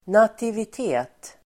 Ladda ner uttalet
nativitet substantiv, birth rate Uttal: [nativit'e:t] Böjningar: nativiteten Definition: antal födslar, födelsetal birthrate substantiv, födelsetal , nativitet nativity , Nativitet , ursprung , lurar